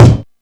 Kick (67).wav